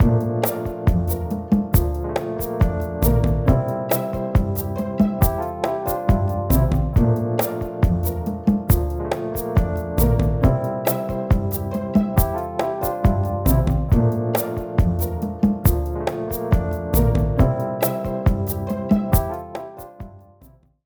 69bpmBossa05-3bar.wav